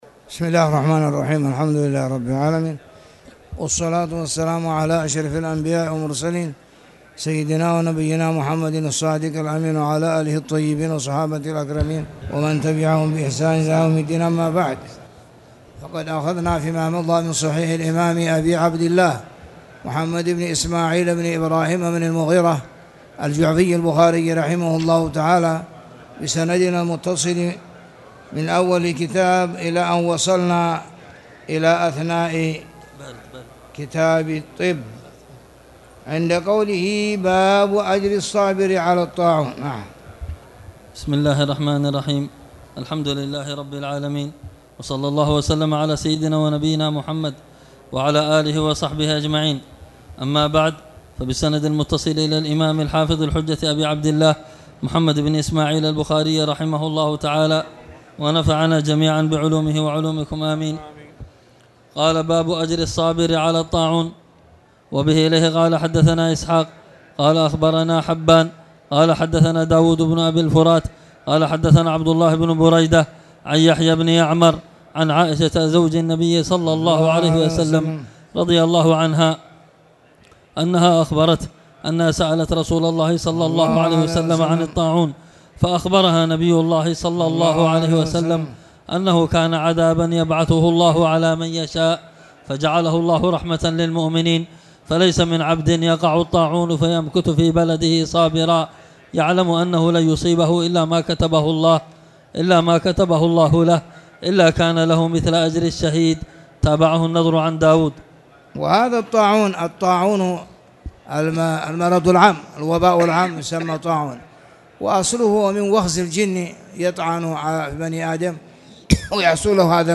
تاريخ النشر ٣ ربيع الثاني ١٤٣٨ هـ المكان: المسجد الحرام الشيخ